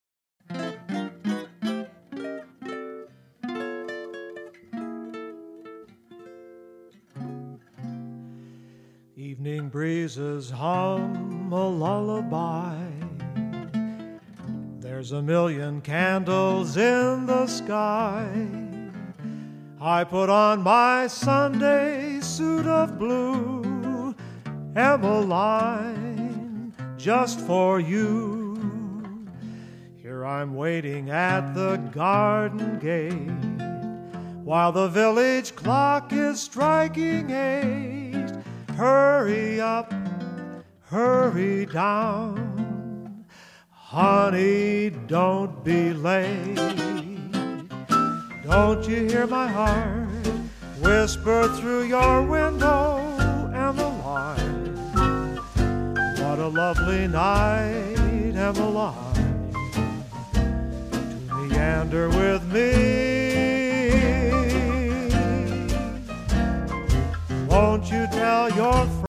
cornet/vocals
string bass